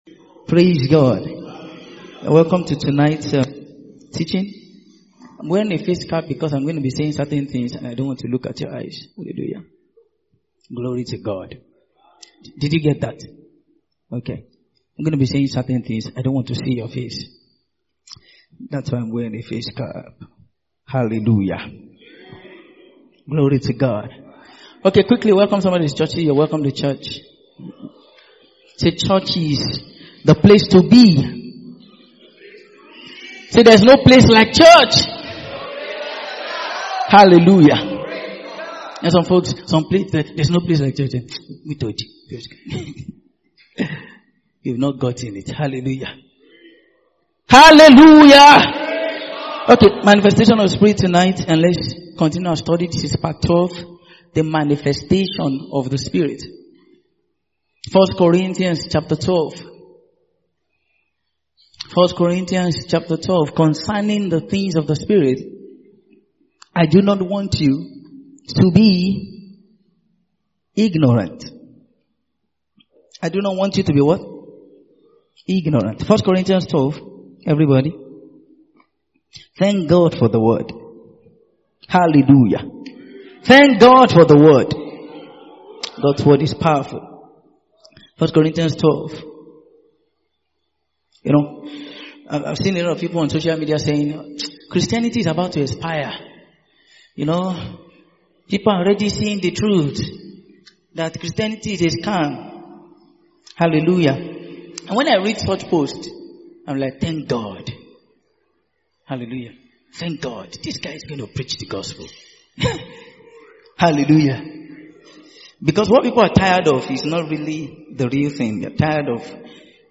Mid-Week Sermons